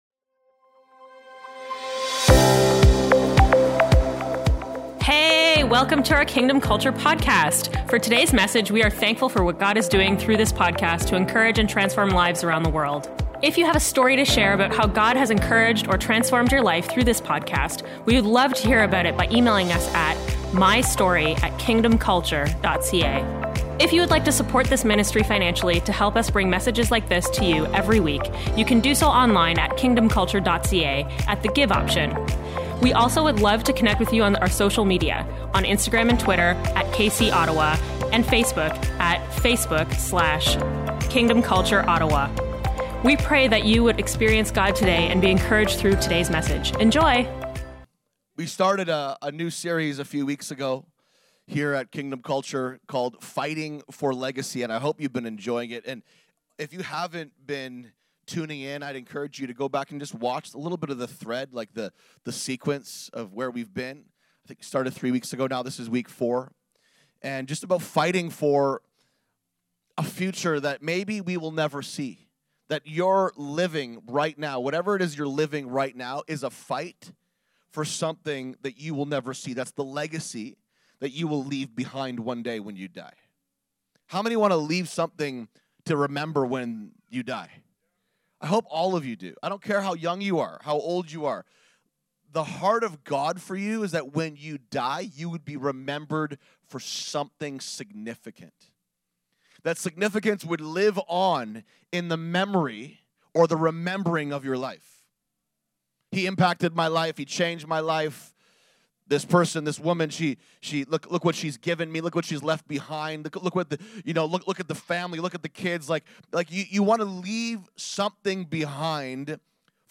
Part 4 of our Fighting for Legacy Message Series.